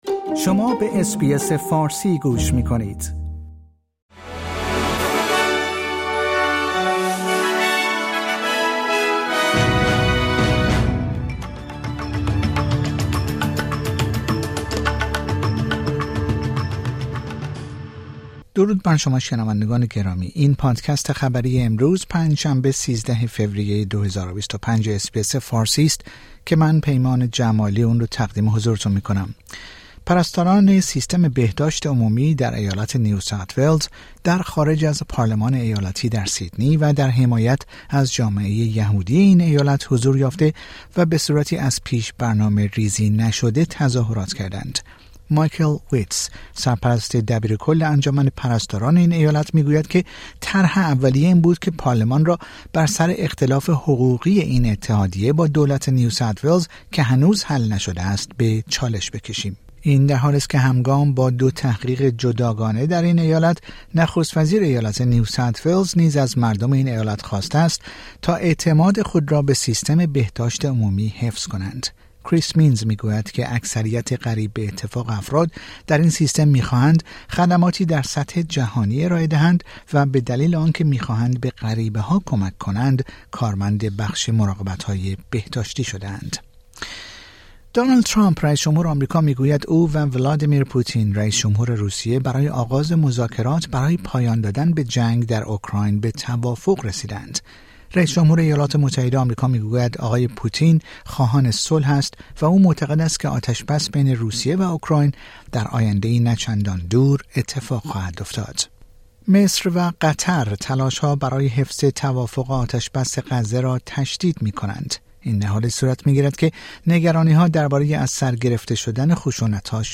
در این پادکست خبری مهمترین اخبار استرالیا در روز پنج شنبه ۱۳ فوریه ۲۰۲۵ ارائه شده است.